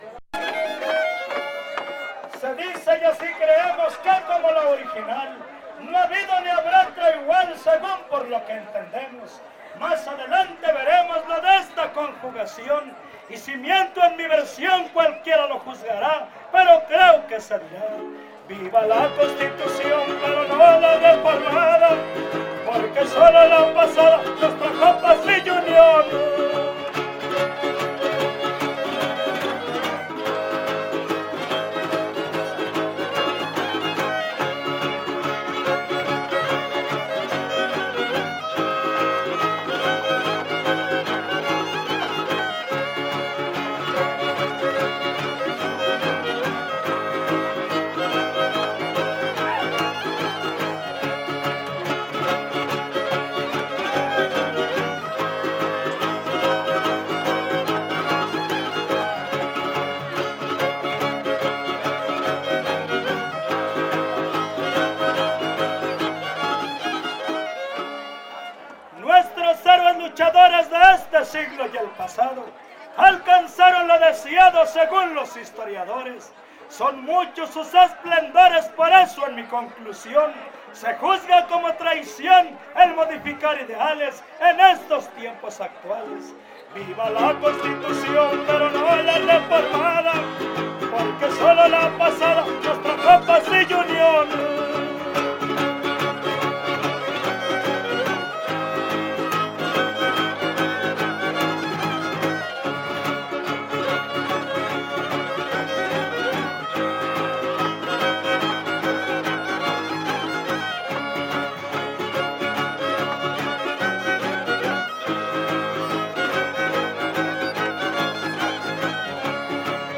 Décima
Huapango arribeño
Guitarra Violín Vihuela
Topada ejidal: Cárdenas, San Luis Potosí